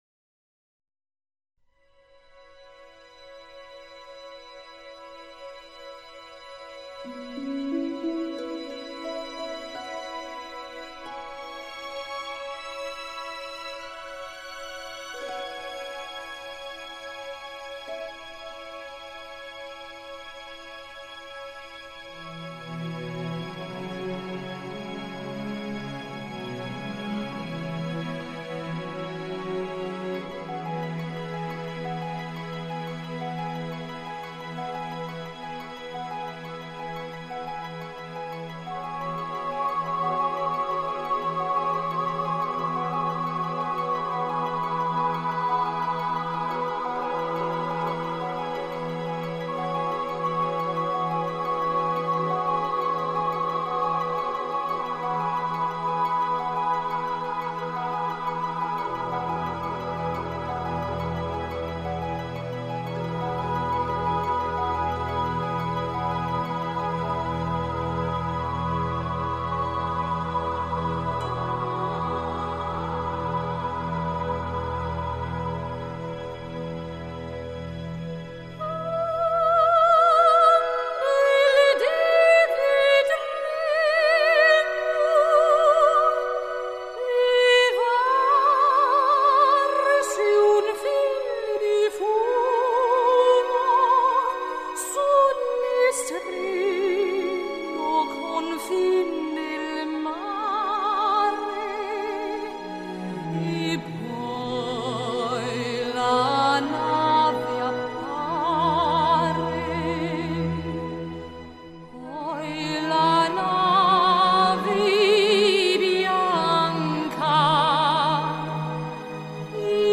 音乐类型：古典音乐
这位现年二十七岁的年轻女歌手，以不分领域的选曲、无压迫感的轻柔歌喉，在一片传统声乐背景的跨界女声中，格外显得清新脱俗。